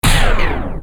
digging.wav